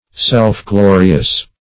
Meaning of self-glorious. self-glorious synonyms, pronunciation, spelling and more from Free Dictionary.